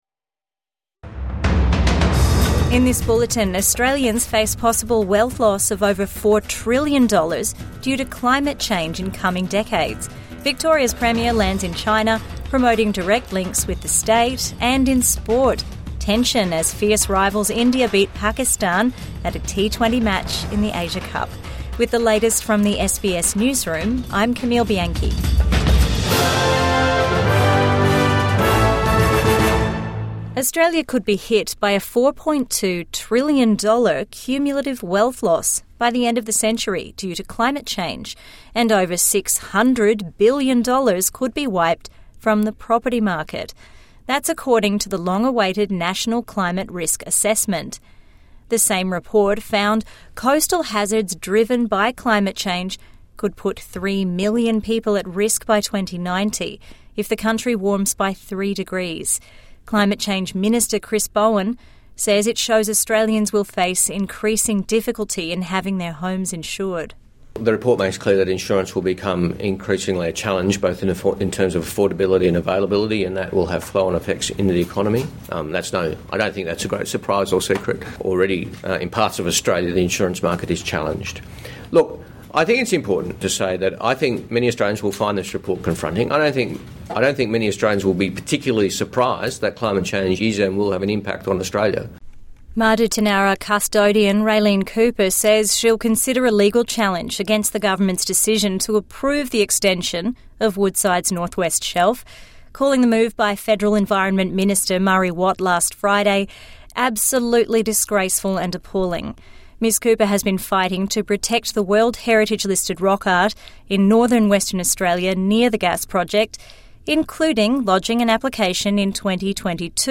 SBS News Updates